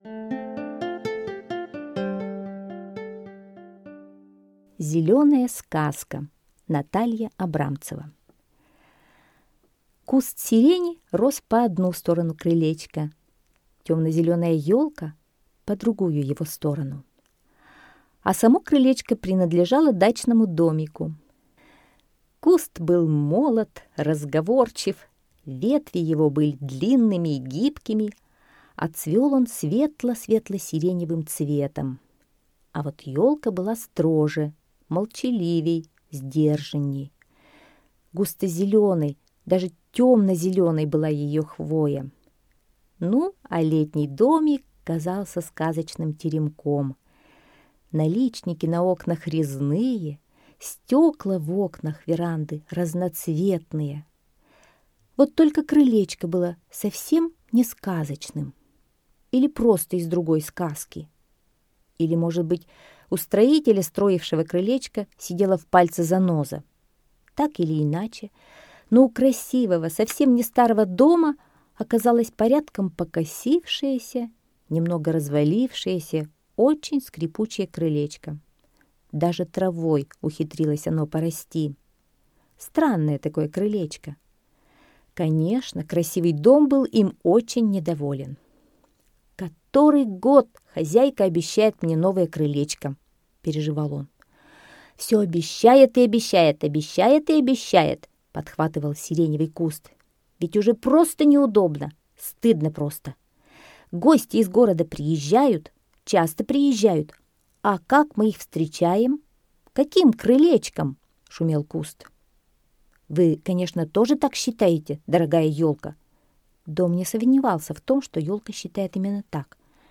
Слушайте Зеленая сказка - аудиосказка Абрамцевой Н. Сказка о кусте сирени, который рос около старого крылечка дачного домика.